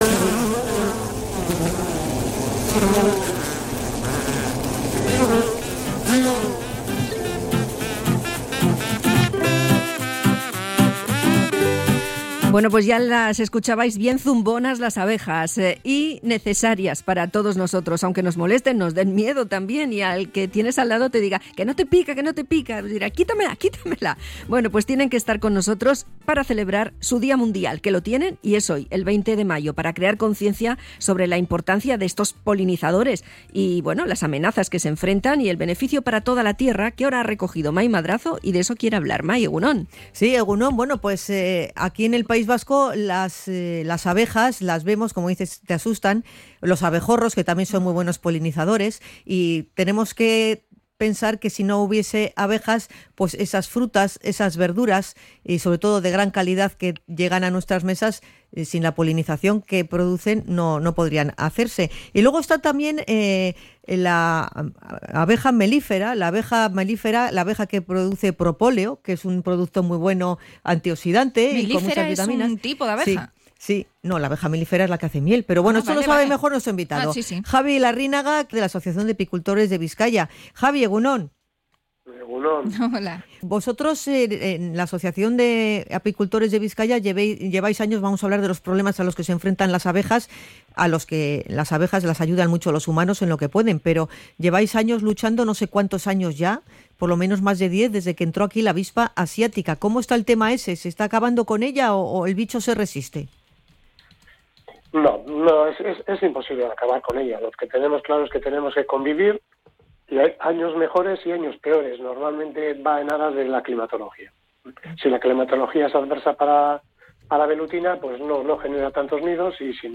Hablamos con el apicultor vizcaíno